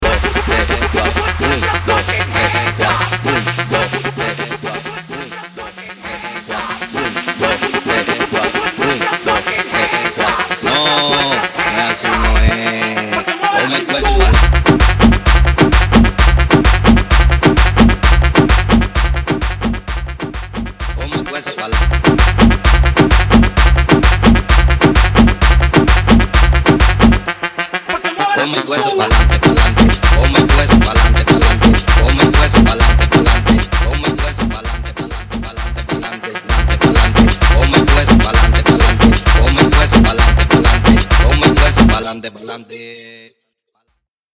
130 / Guaracha